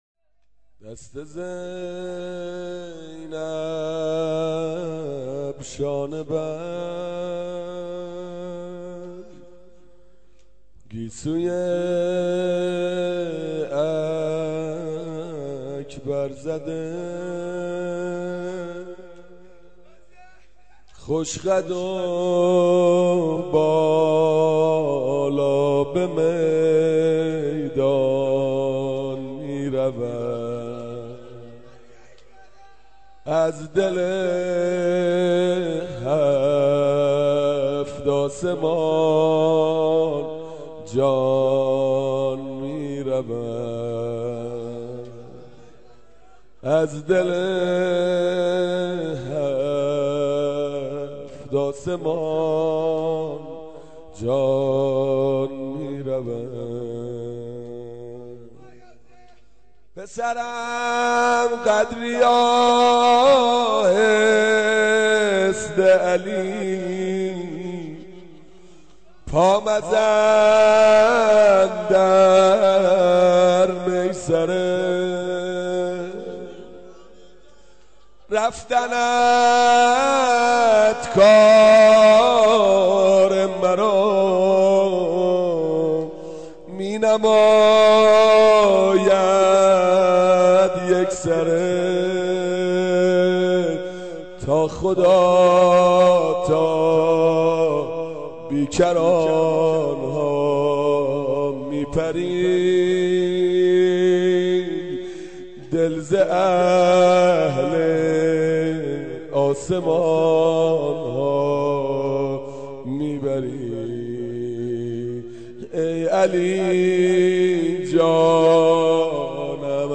مداحی لری, محرم